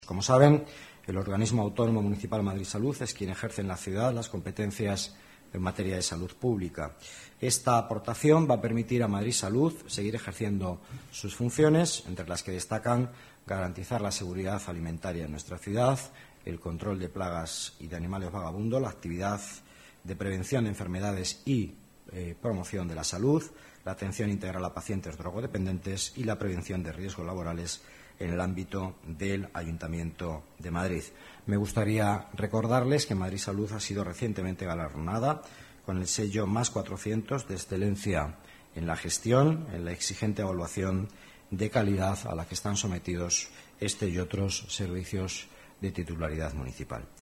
Nueva ventana:Declaraciones vicealcalde de Madrid, Miguel Ángel Villanueva: aportación Madrid Salud, objetivos